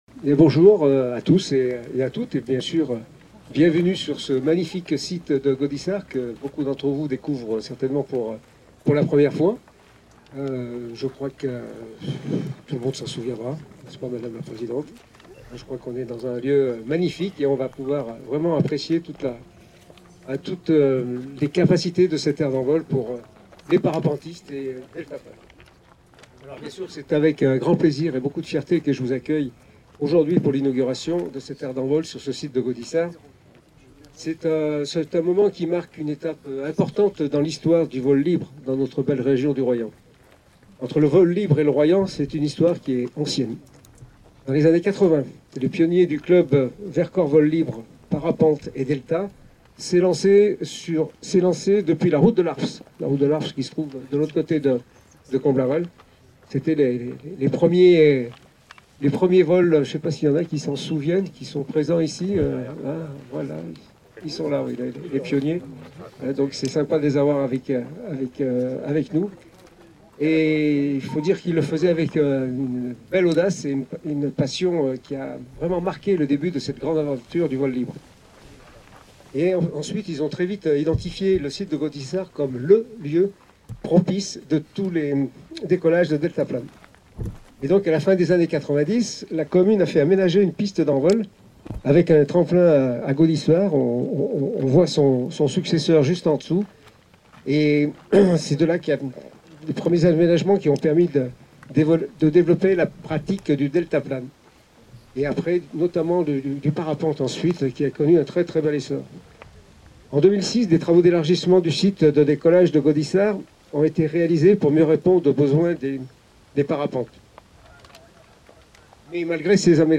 vol-libre-inaug.-discours-elus.mp3